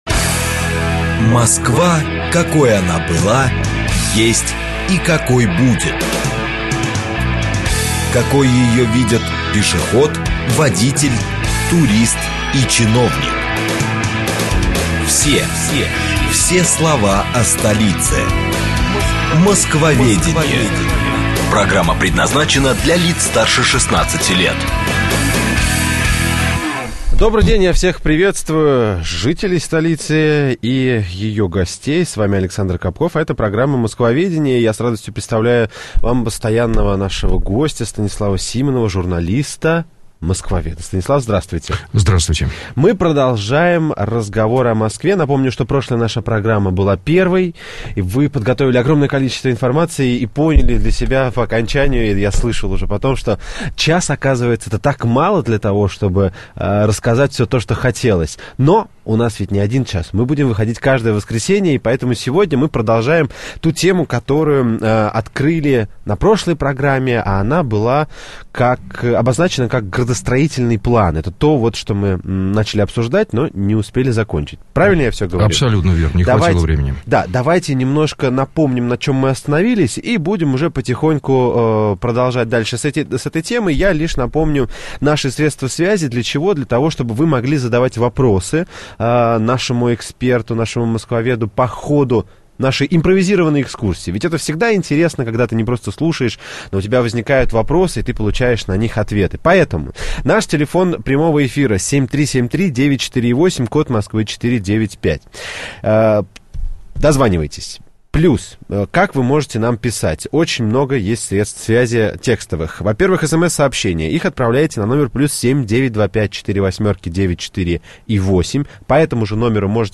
Аудиокнига Градостроительный план Москвы | Библиотека аудиокниг